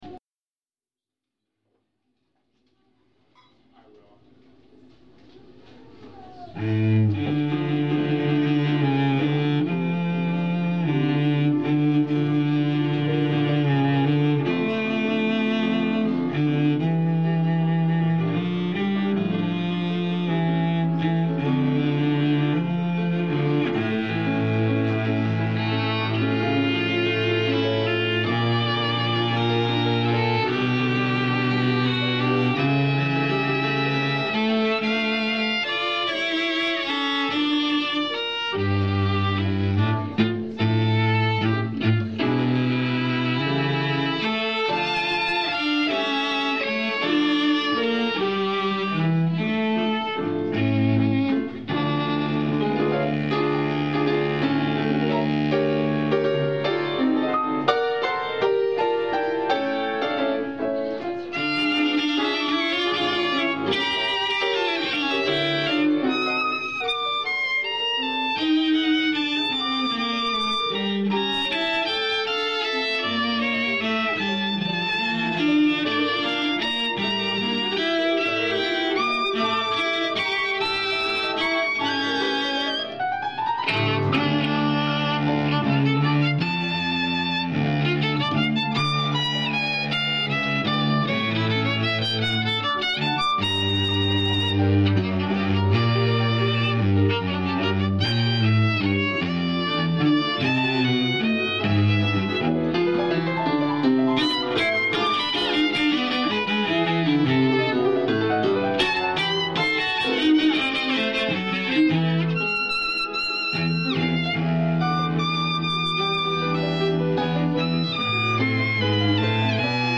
All of the recordings I have listened to so far are completely clipped. Which is to say they were recorded so loud that there is distortion in the recording.
But there were only a few mikes picking up sound.
trio.mp3